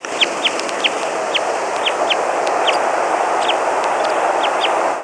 Red Crossbill flight calls
Type 2 in flight.